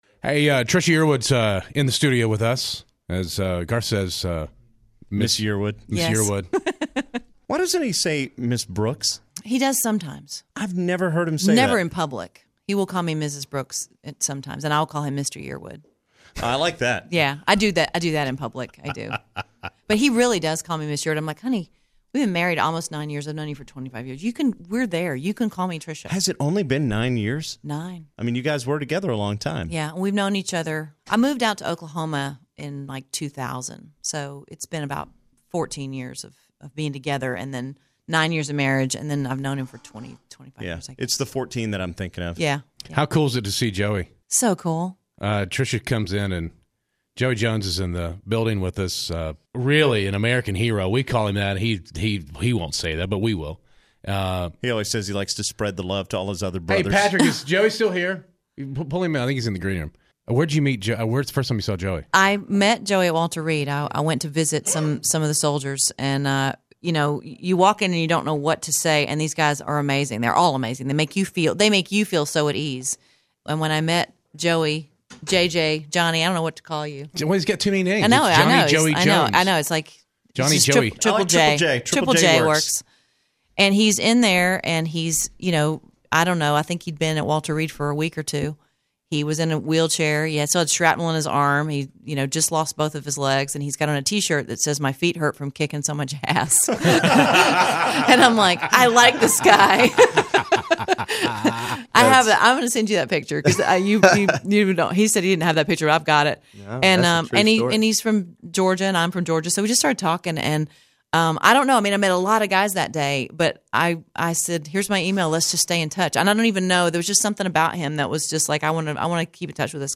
Trisha Yearwood interview
We had a few surprises for Trisha Yearwood when she visited the studio.